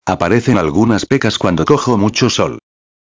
18/08/2011 peca sarda •\ [pe·ca] \• •\ Substantivo \• •\ Feminino \• Significado: Mancha pequeña de color marrón que aparece en la piel, especialmente en la cara, debido a una acumulación de melanina. Origem: de "picar" Exemplo com áudio: Aparecen algunas pecas cuando cojo mucho sol.